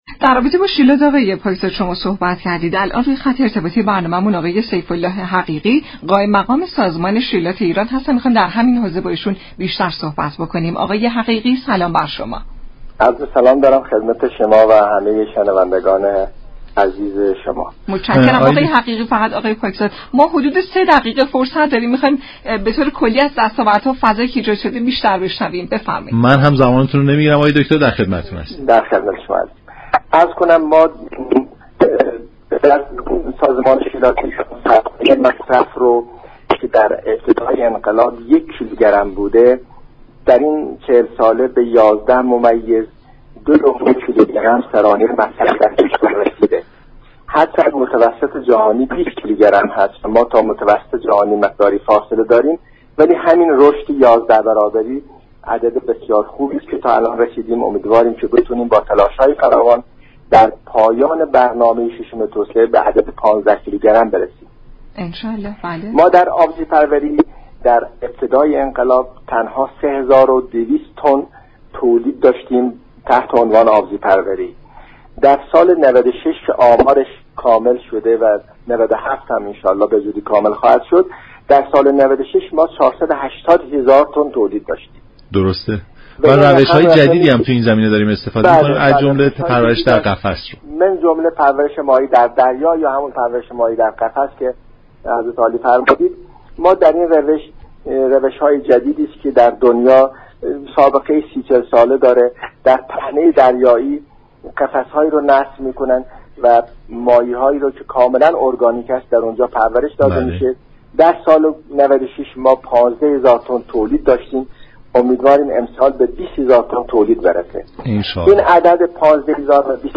قائم مقام شیلات ایران در گفت و گو با رادیو ایران گفت: كشورمان در طول این سالیان توانسته در آبهای شمال و جنوب ایران به روش تولید در قفس، پرورش ماهی داشته باشد.